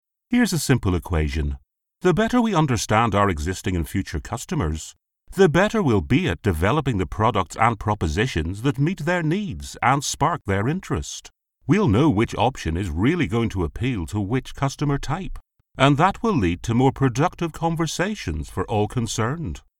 E-learning
I have a soft engaging Northern Irish Accent
Baritone , Masculine , Versatile . Commercial to Corporate , Conversational to Announcer . I have a deep, versatile, powerful voice, My voice can be thoughtful , authoritative , storytelling and funny . Confident and able to deliver with energy , humour and conviction .
RODE NT1A . TECPORTPRO , ISOLATED SOUND TREATED BOOTH. EDIT WITH AUDACITY